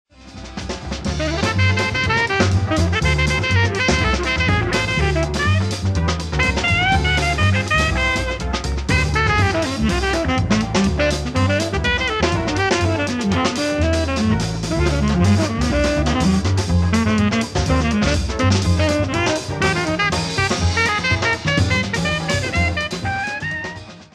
LIVE AT TOURCOING, FRANCE 11/11/1993
サウンドボード録音